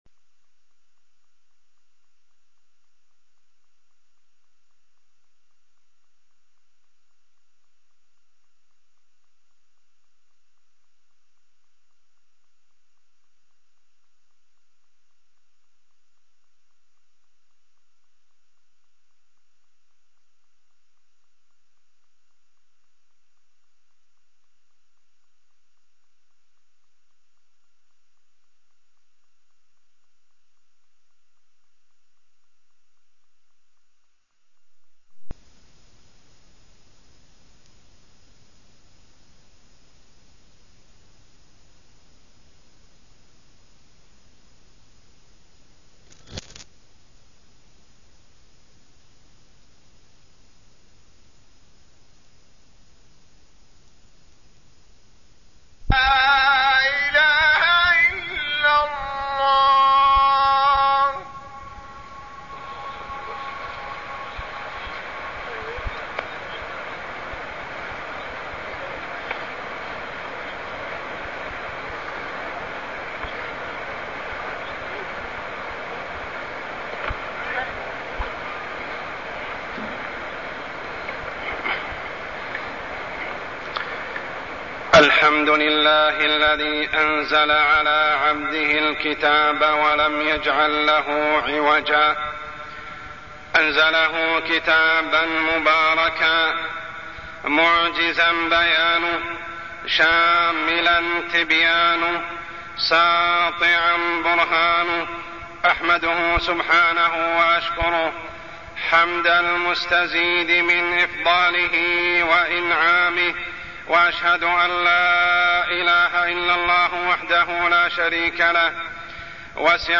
تاريخ النشر ٦ صفر ١٤٢٠ هـ المكان: المسجد الحرام الشيخ: عمر السبيل عمر السبيل المعجزة الكبرى-القرآن الكريم